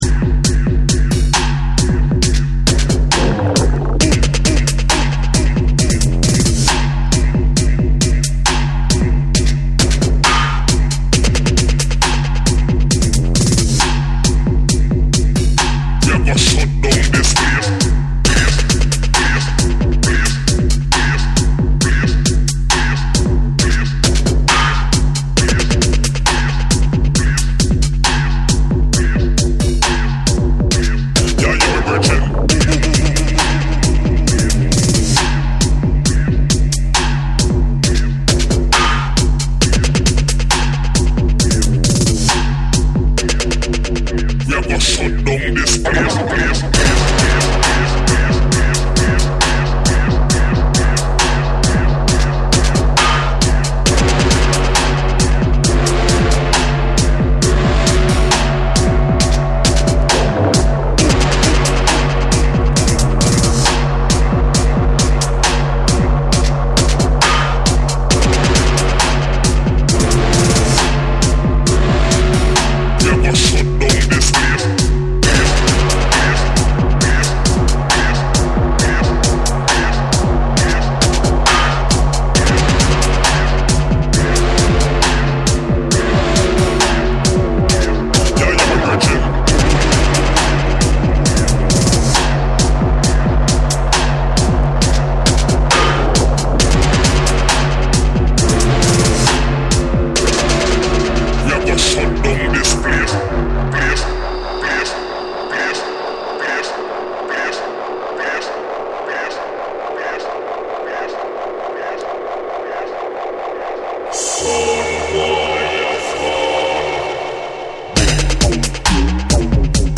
リズミカルなビートに、重量感あるサブベースが絡むキラー・チューン
BREAKBEATS / DUBSTEP